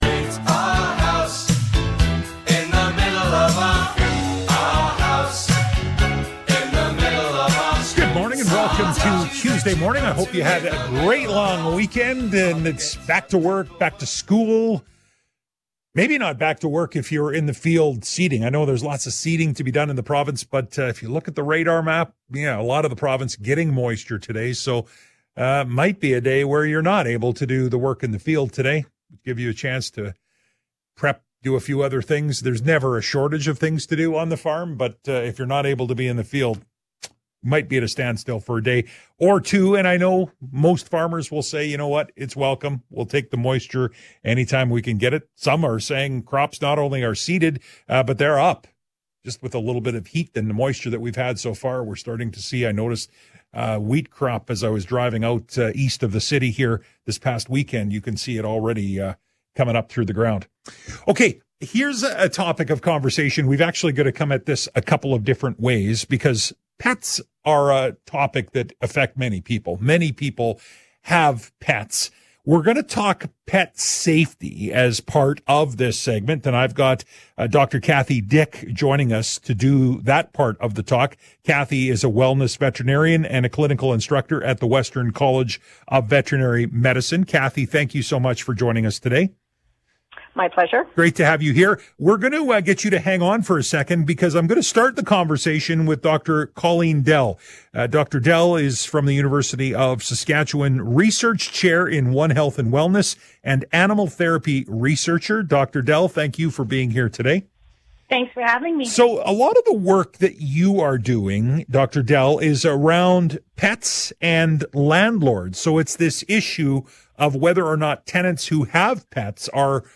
630 CHED Afternoons - Edmonton, January 18, 2024 RADIO INTERVIEW